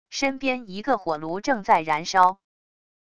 身边一个火炉正在燃烧wav音频